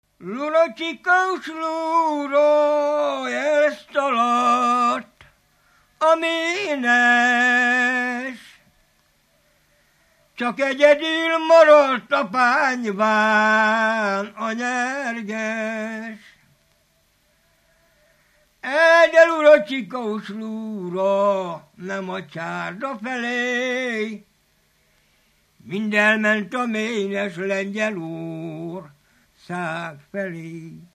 Alföld - Szabolcs vm. - Kállósemjén
Stílus: 4. Sirató stílusú dallamok
Kadencia: 5 (4) 5 1